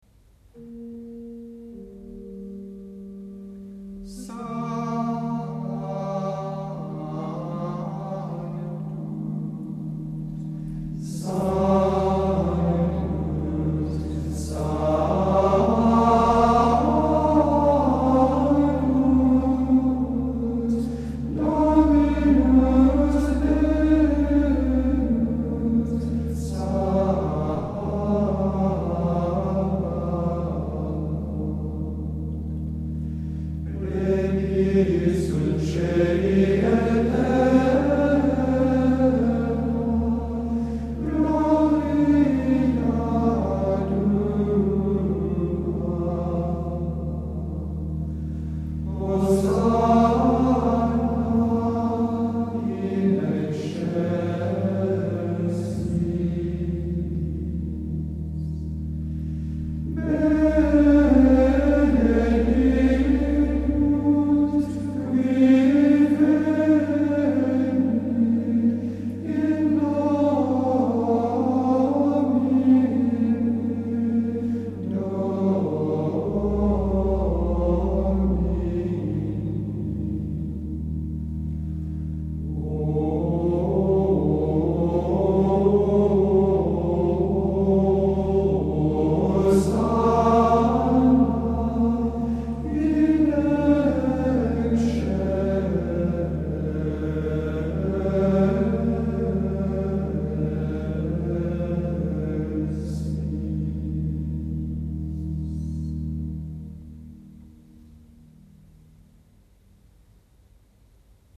• gloria grégorien sanctus agnus
C’est un beau 5e mode mais sa modalité est relativement imprécise car on n’entend jamais la sous-tonique, c’est-à-dire le Mi.
Sa riche expression en fait un chant très agréable et facilement aimable.
Le premier Sanctus est attaqué de façon bien ferme sur son pressus initial : un double Do vivant et appuyé.
Le second Sanctus est plus humble, plus doux.
Cet élan est très beau, large, bien épanoui, chaleureux.
Ce hosánna est doux, même si l’accent de hosánna est bien ferme.
La finale est très belle, très pleine, avec ses deux beaux torculus, neumes ternaires qui élargissent le mouvement et amènent assez solennellement la dernière cadence de ce beau Sanctus.